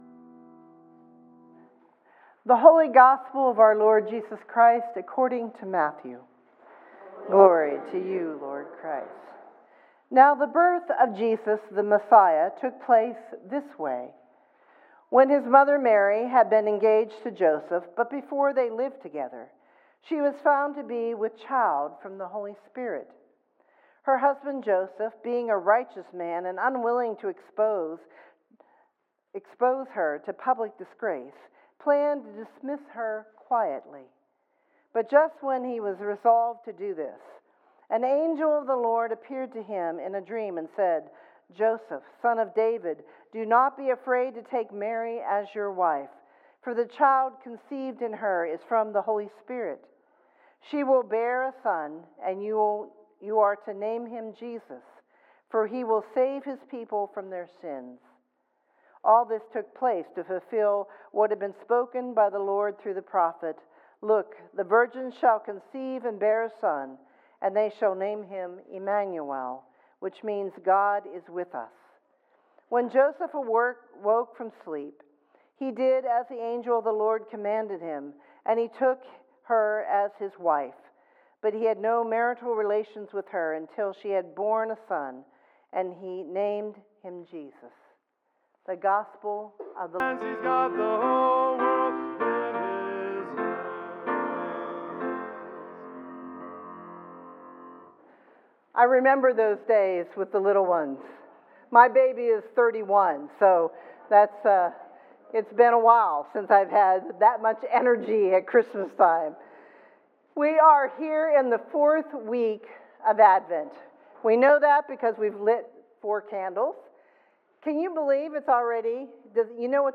Fourth Sunday in Advent, Matthew 1:18-25